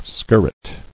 [skir·ret]